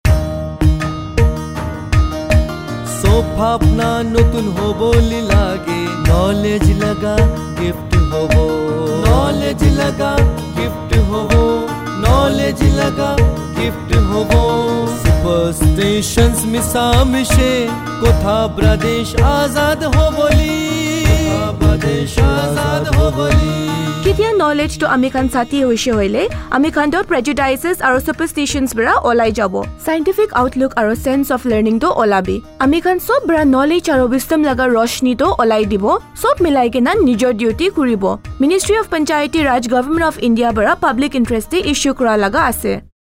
175 Fundamental Duty 8th Fundamental Duty Develop scientific temper Radio Jingle Nagamese